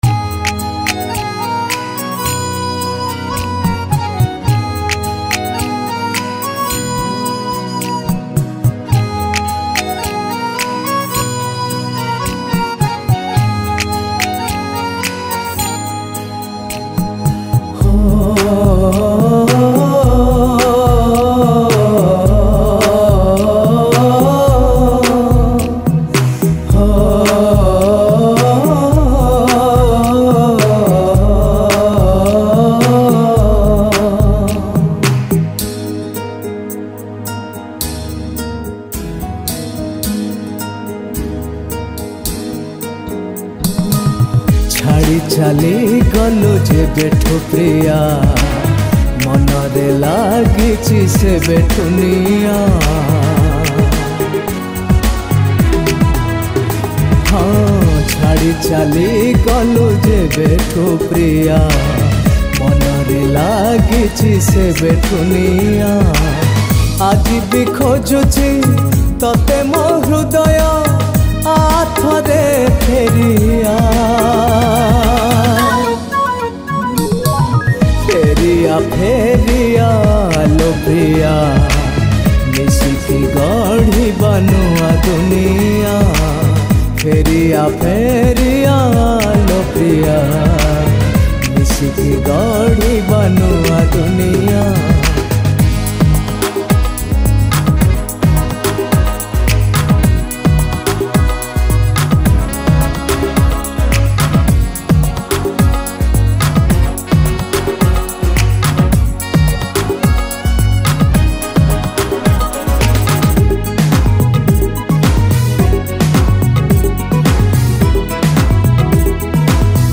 Odia Sad Song